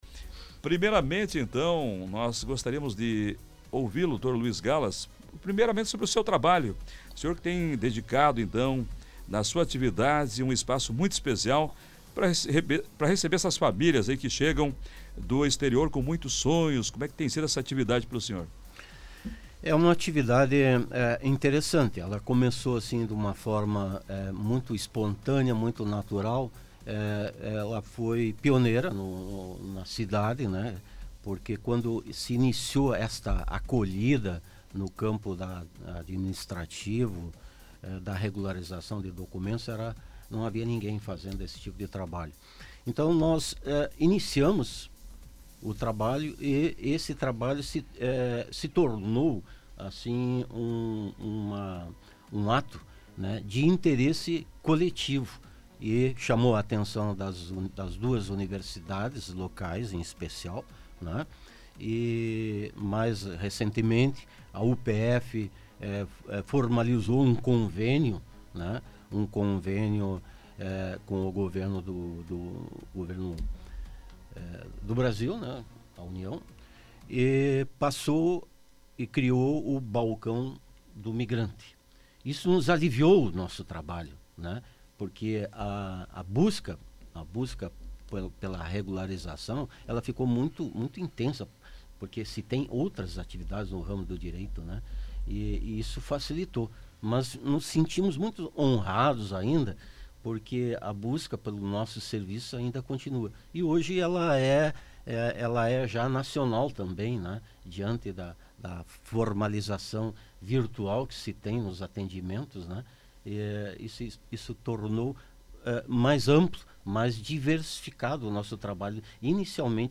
ENTREVISTA-ASSOCIACAO-SENEGALESES.mp3